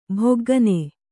♪ bhoggane